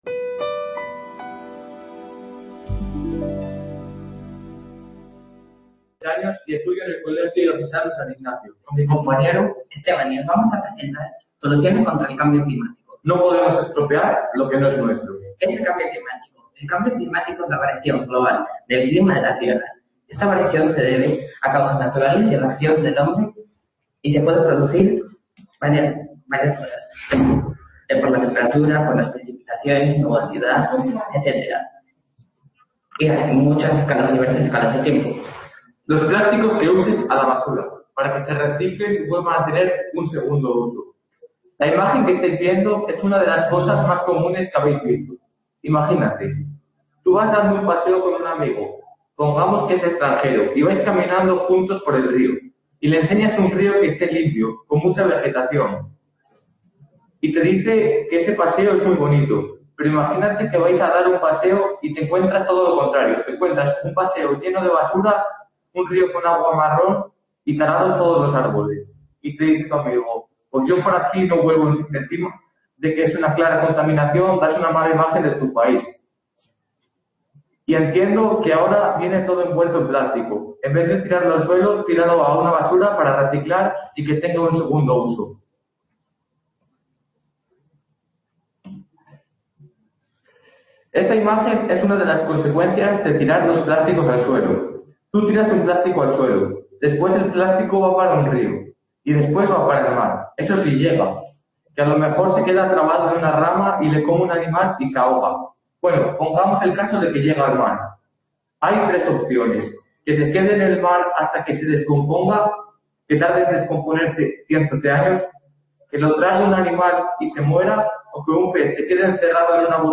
2 Edición Congreso de Jóvenes Expertos. Cambio Climático. (sala Ponferrada)